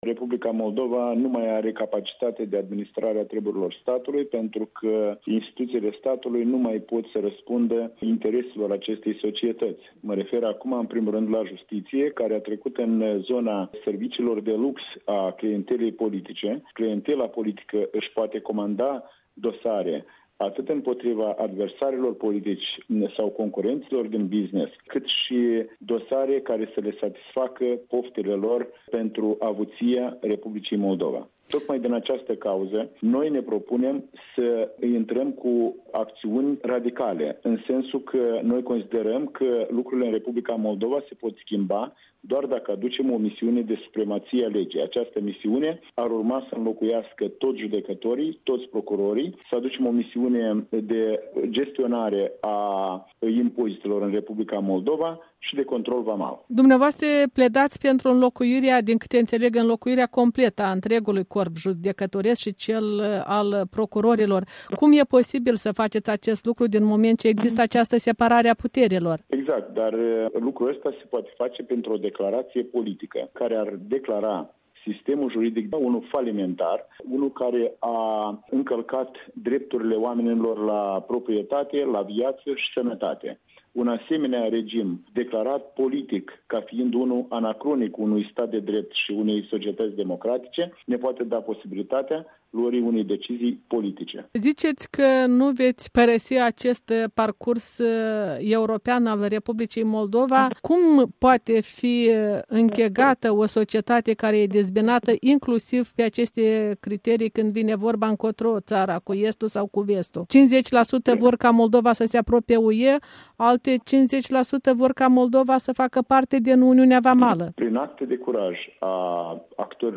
Un interviu electoral cu Sergiu Mocanu